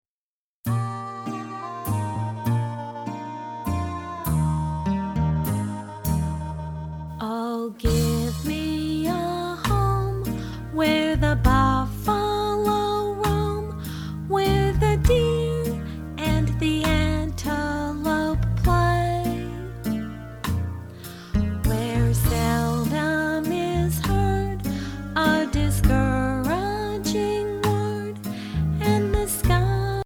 children's favorite play-songs